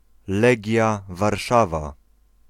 Legia Warszawa (Polish: [ˈlɛɡʲja varˈʂava]